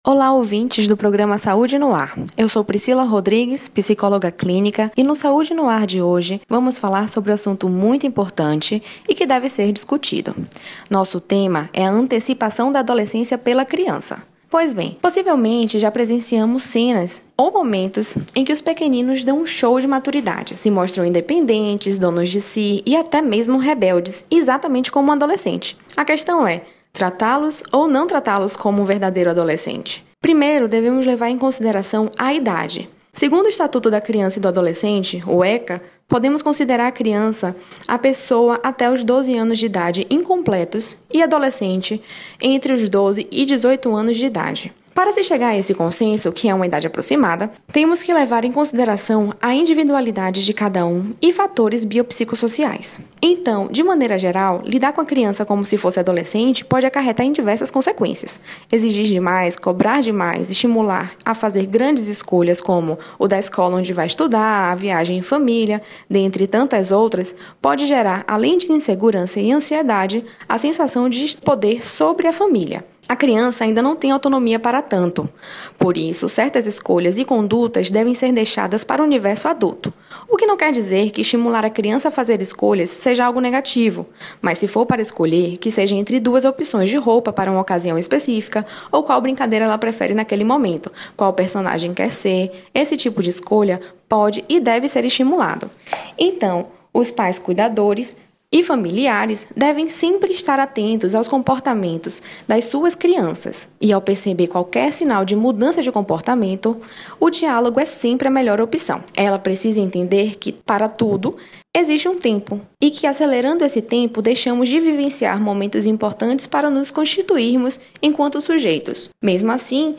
O assunto foi tema do Quadro: Mente Sã em Corpo São”, exibido toda quinta-feira no Programa Saúde no ar, veiculado pela Rede Excelsior de Comunicação: AM 840, FM 106.01, Recôncavo AM 1460 e Rádio Saúde no ar / Web. .